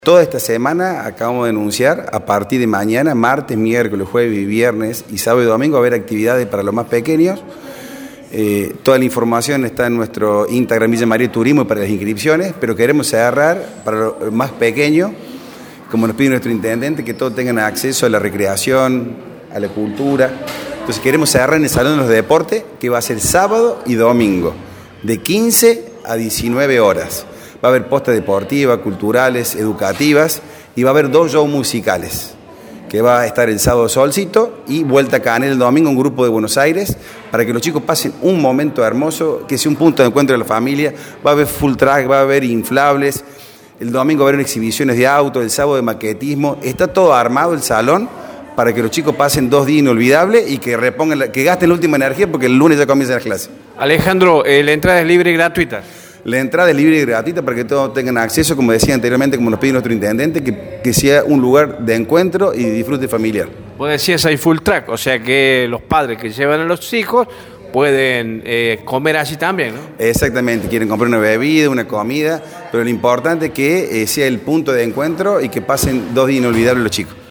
El presidente del Ente Deporte y Turismo, Alejandro Mana contó algunos detalles de la actividad.